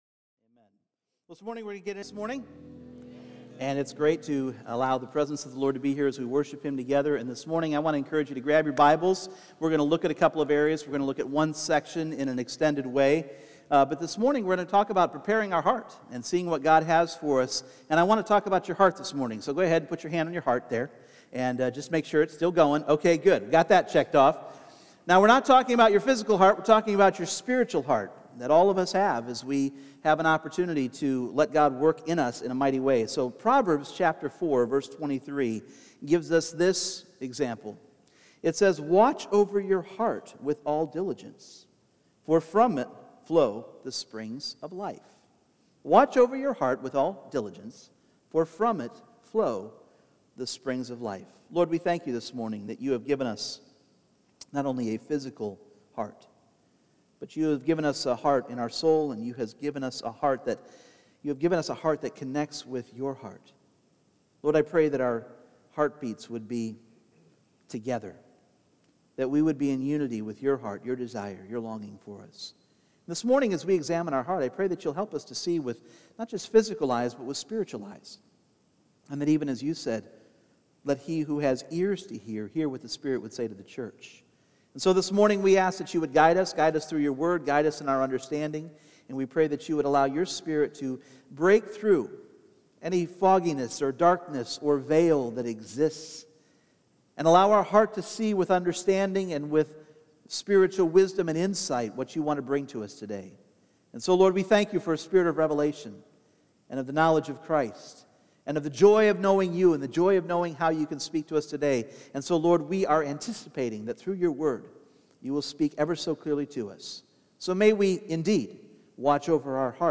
Well, it all comes down to the condition of our heart. Let this message, drawn from Jesus' parable of the Seed and the Sower, impact your life and remind you of the power of God's Word in your life, as well as some common enemies of the Word of God. This sermon uses a PowerPoint, but the audio message still conveys the heart of this message for our hearts!
Service Type: Sunday Morning